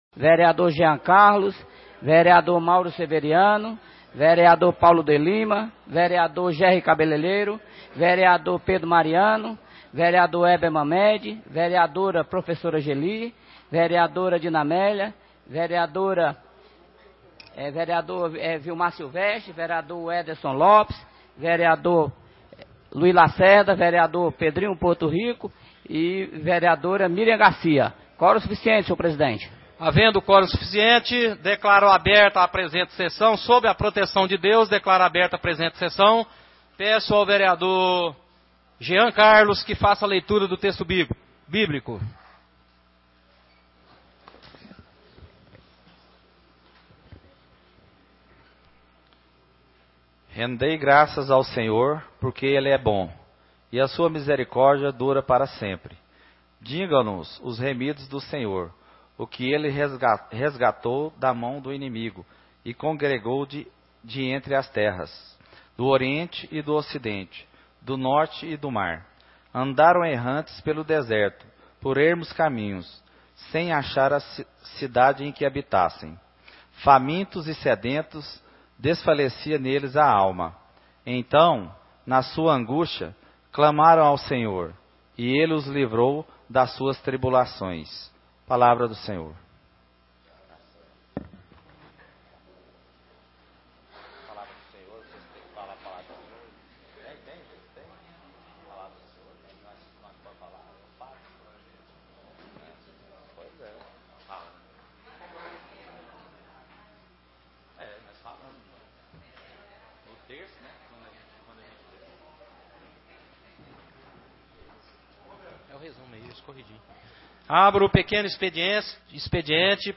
Sessão Ordinária 20/02/2013 - Quarta- Feira